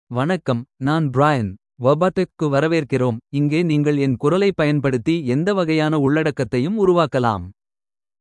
Brian — Male Tamil AI voice
Brian is a male AI voice for Tamil (India).
Voice sample
Listen to Brian's male Tamil voice.
Male